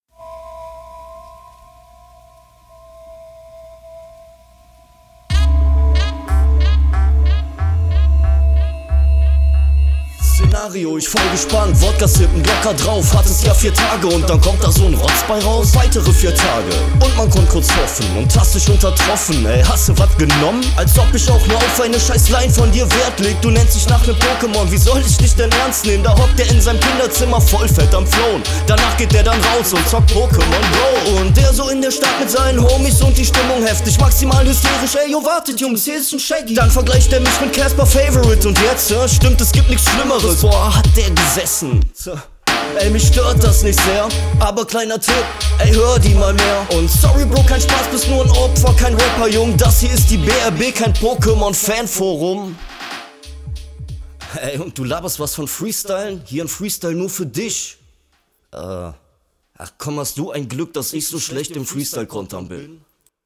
Der Beat ist richtig geil und du rappst auch stabil drauf.